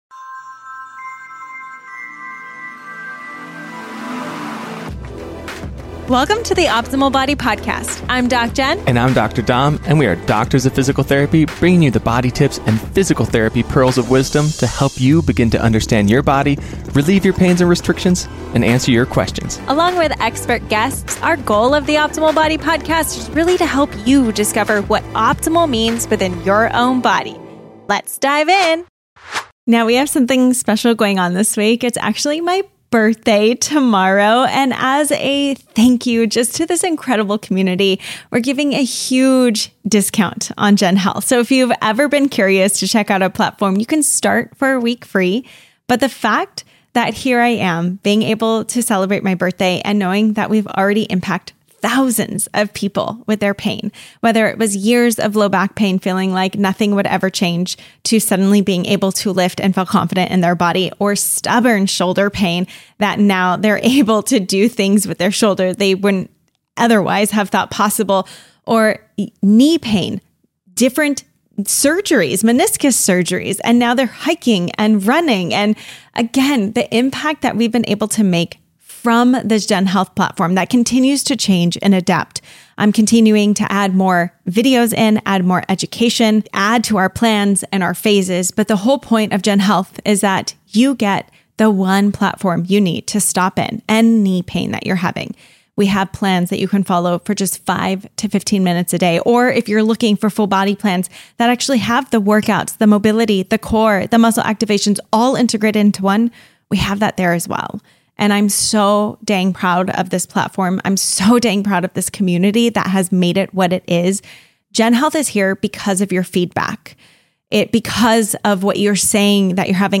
The conversation covers common misconceptions, the lack of pain education in medicine, and practical strategies to relieve chronic pain.